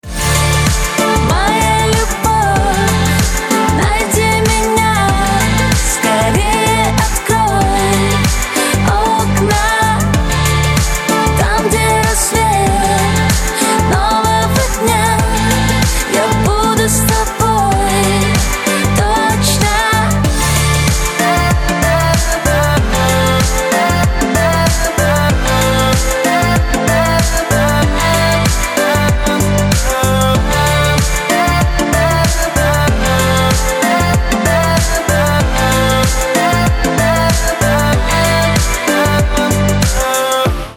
• Качество: 320, Stereo
поп
женский вокал
dance
дуэт
женский и мужской вокал